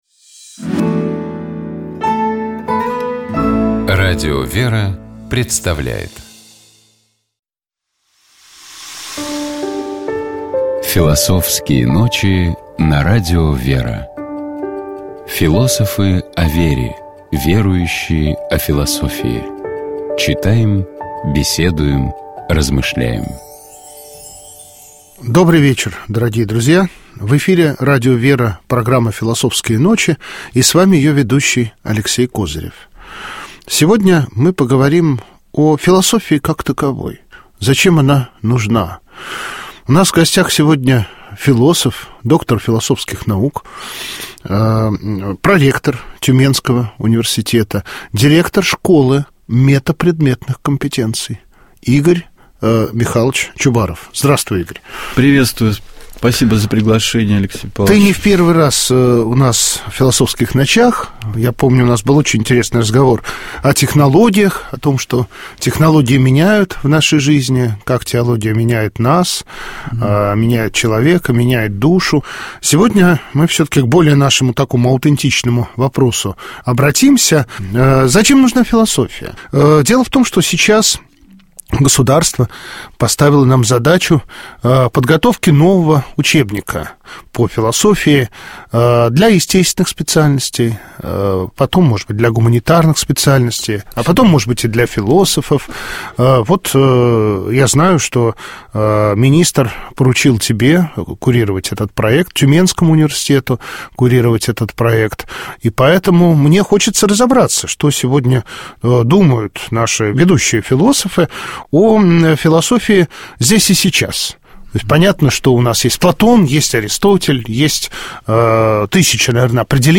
Текст одного из антифонов Страстной Пятницы повествует о предательстве Христа одним из Его учеников — Иудой. Давайте поговорим об этом песнопении и послушаем его отдельными фрагментами в исполнении сестёр Орского Иверского женского монастыря.